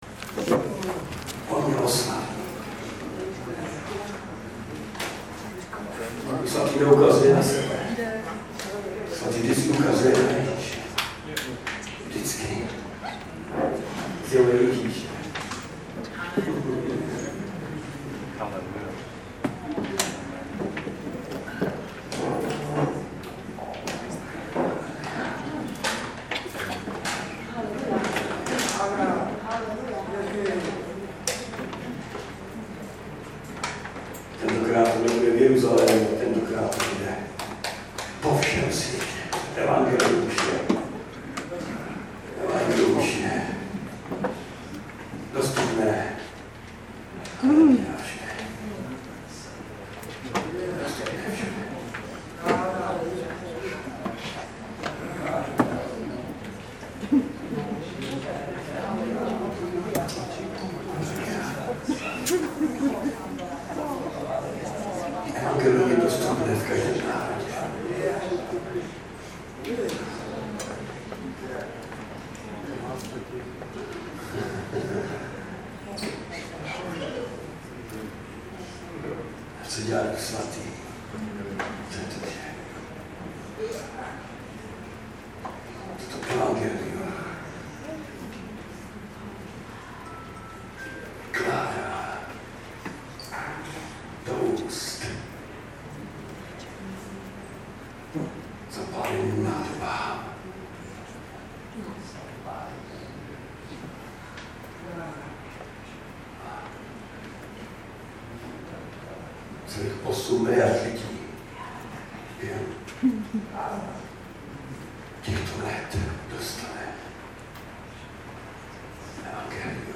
Ale hej. mam ho přece tady. kazani Měl bych už ležet v posteli, a spát.